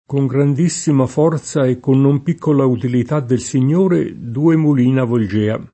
koj grand&SSima f0rZa e kkon nom p&kkola utilit# ddel Sin’n’1re due mul&na volJ%a] (Boccaccio) — sim. i top.